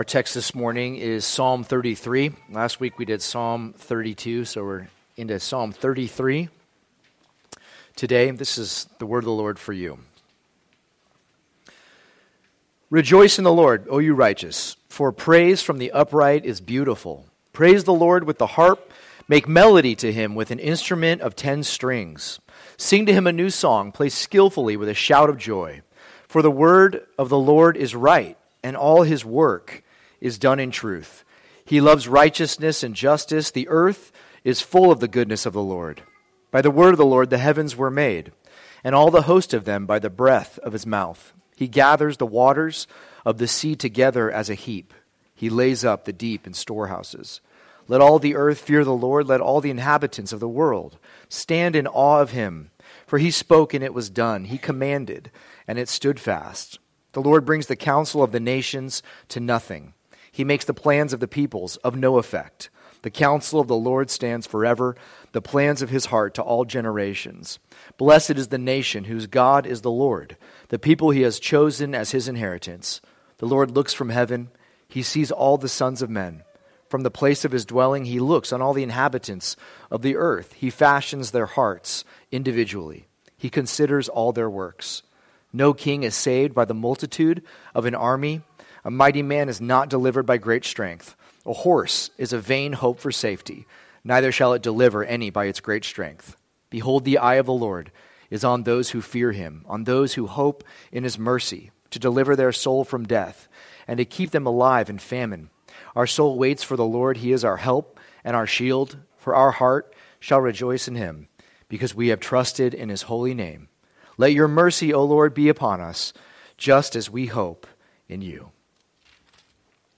2024 Creator & King Preacher